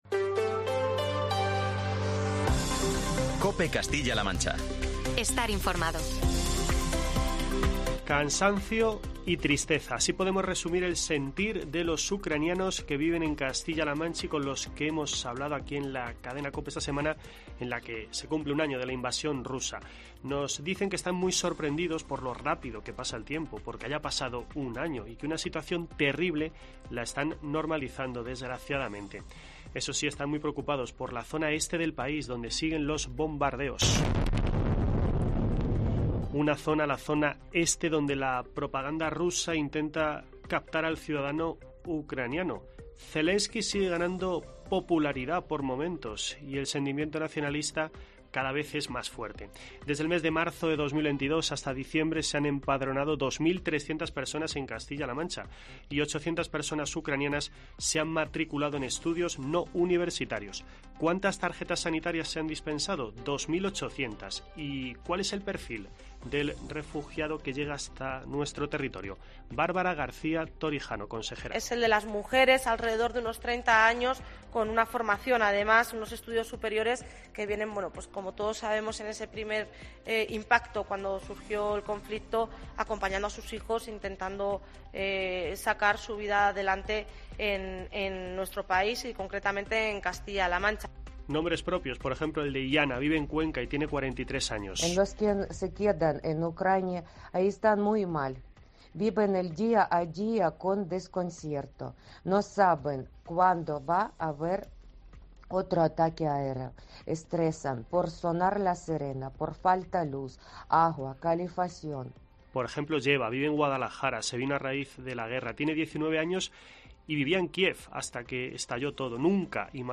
COPE te cuenta los testimonios de los ciudadanos que se han trasladado a vivir a la región en los últimos doce meses